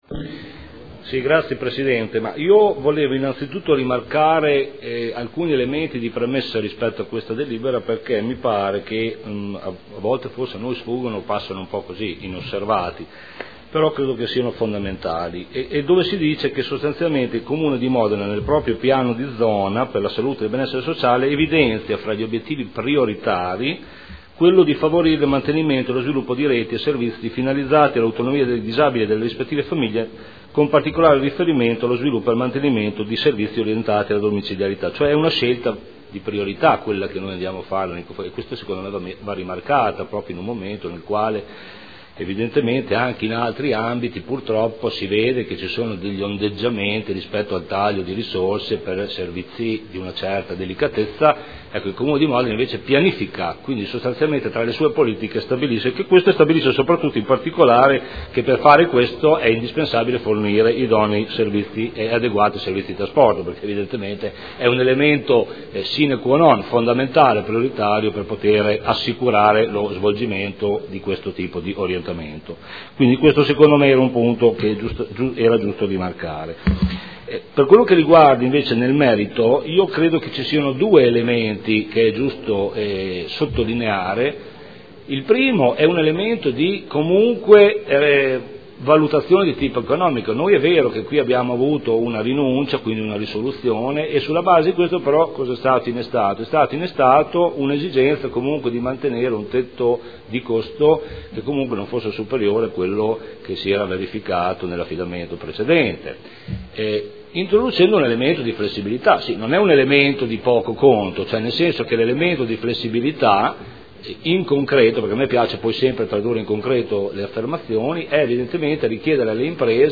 Giancarlo Campioli — Sito Audio Consiglio Comunale